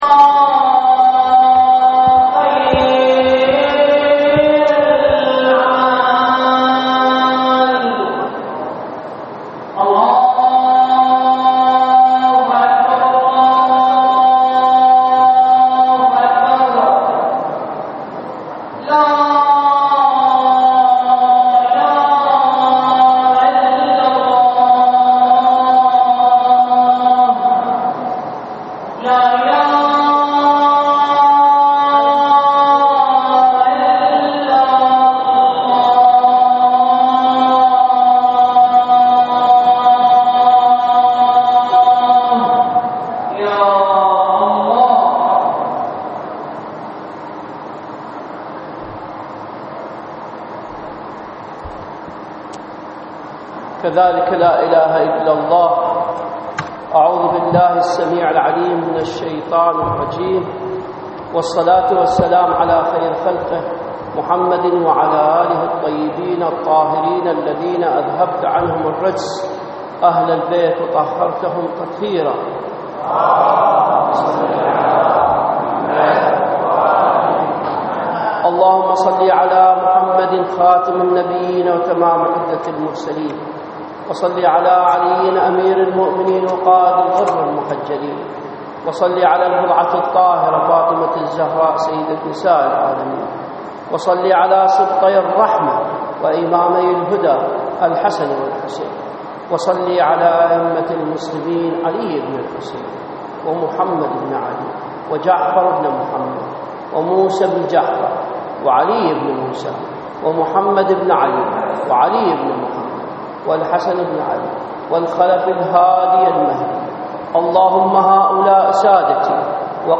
صلاة الجمعة في مدينة الناصرية - تقرير صوتي مصور -
للاستماع الى خطبة الجمعة الرجاء اضغط هنا